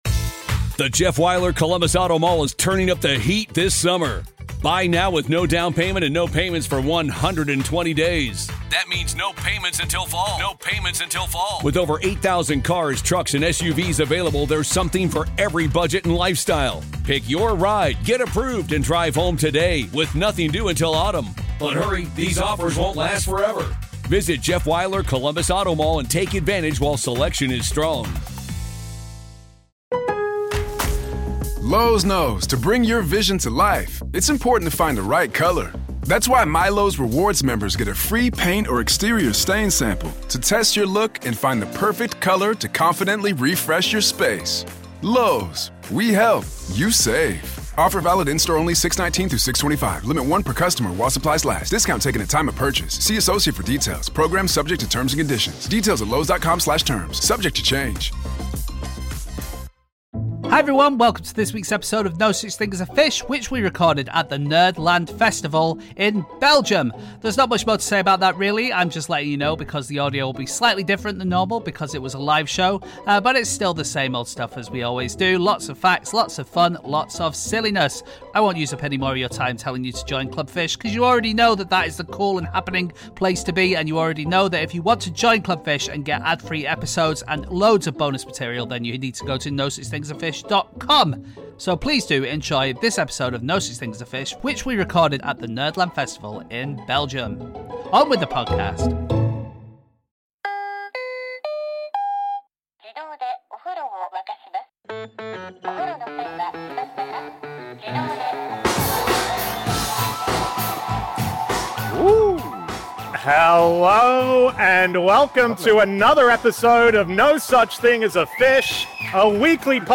Live from the Nerdland Festival in Belgium